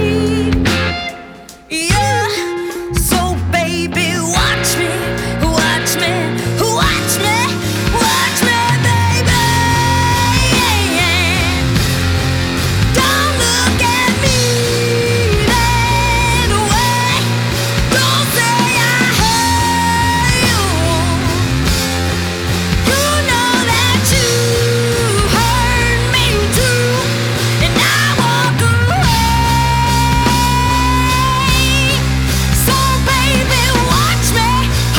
Rock Blues
2017-01-14 Жанр: Рок Длительность